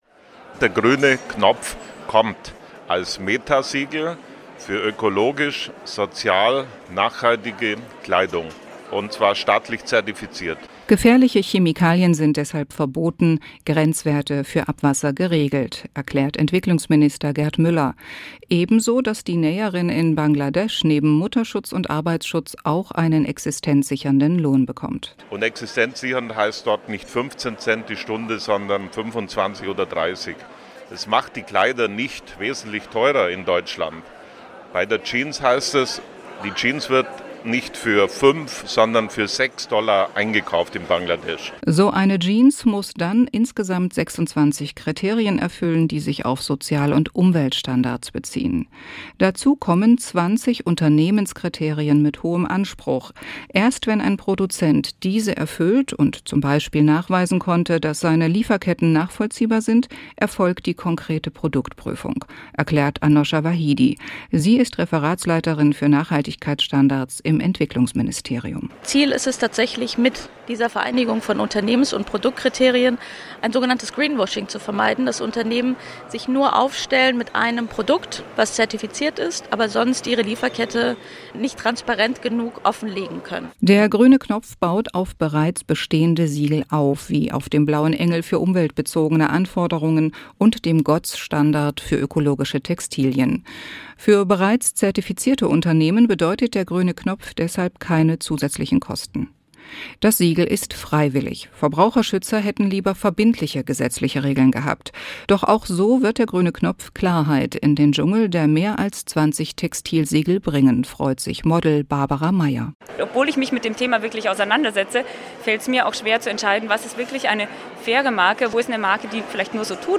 Nachrichten „Dann kostet die Jeans nicht 5, sondern 6 Dollar.“